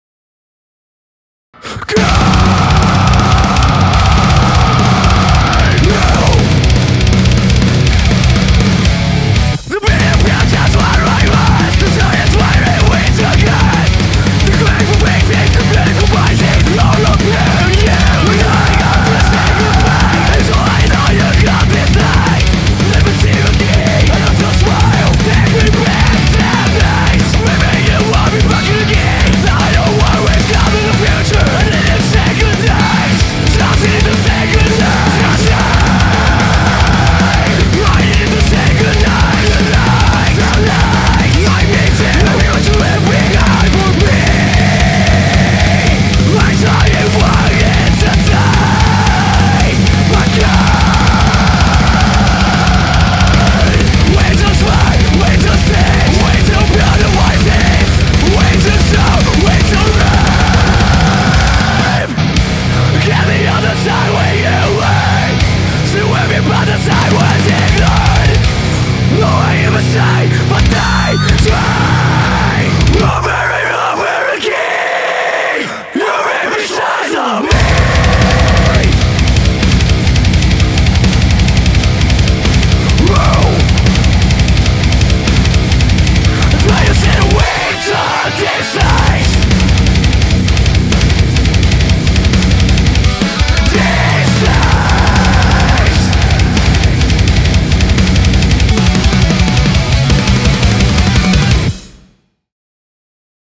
BPM80-244
Audio QualityPerfect (High Quality)